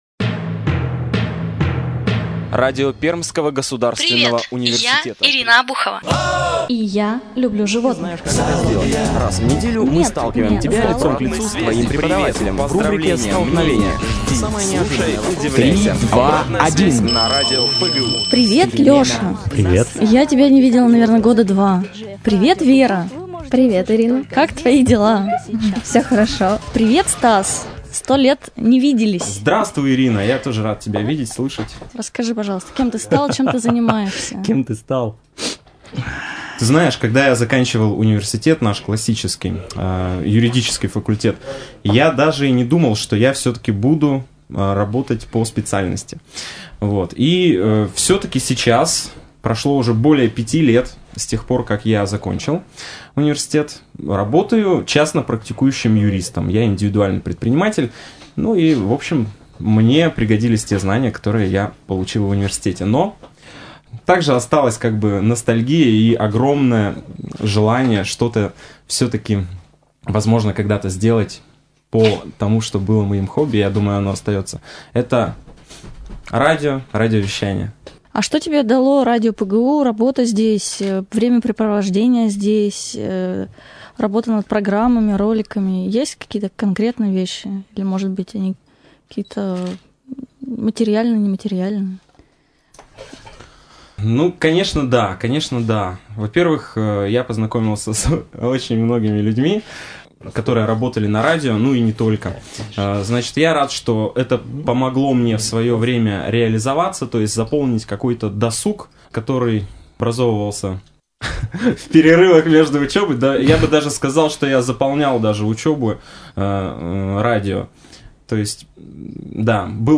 В программе (во второй половине) использованы материалы радиопередач 2001 года.